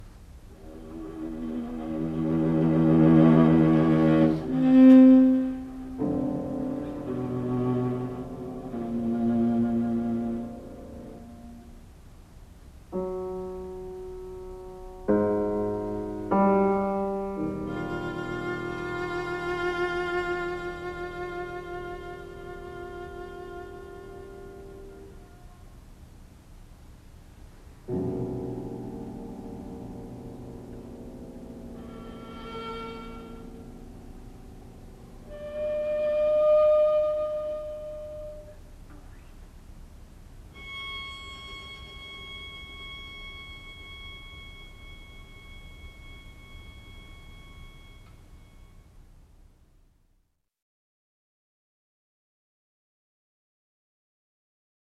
When I see it, I always think of the atonal Webern.
Three Little Pieces for Cello and Piano in which he verges on silence, but it is a silence that is not empty.